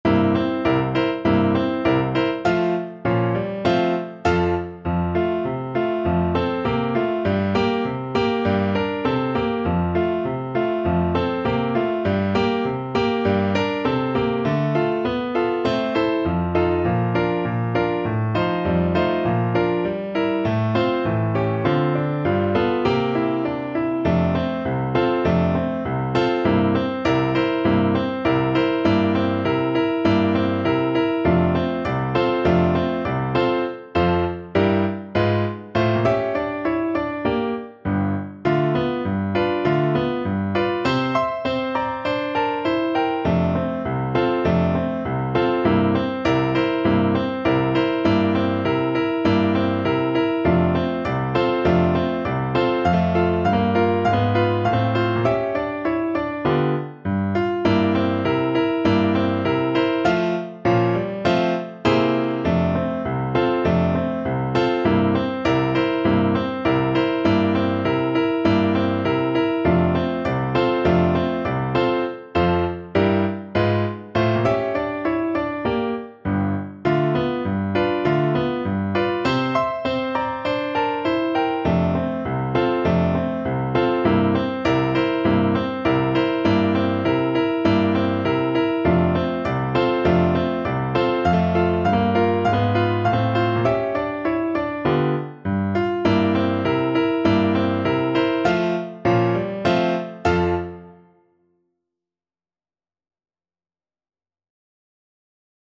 Moderato =c.100